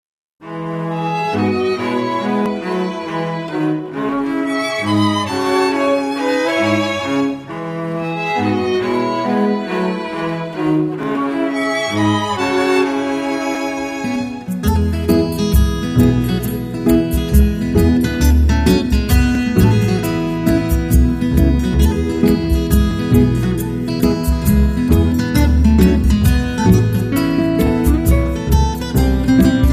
viola caipira